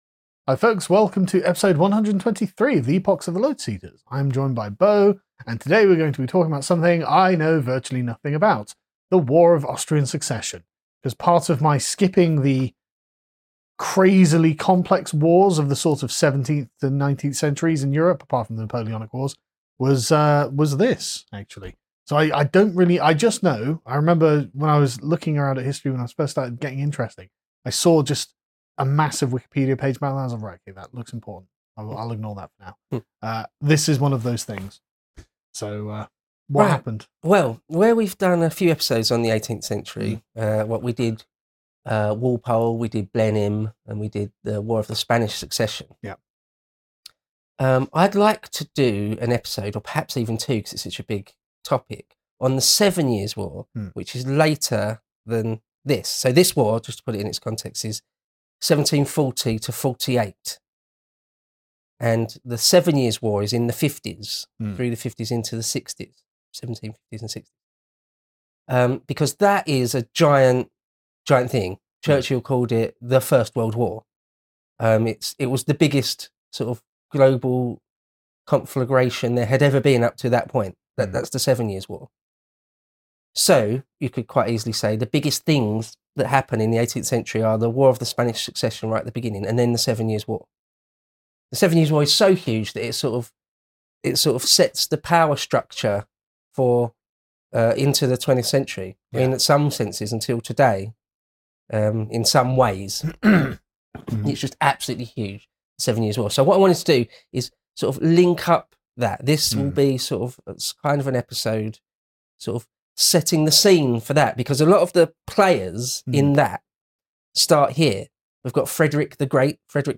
chat about the European wide conflict that was sparked by the death of a Holy Roman Emperor who left no male heirs; The War of the Austrian Succession. From Frederick the Great in Silesia, to the field of Culloden in Scotland, the balance of power in Europe was reworked yet again.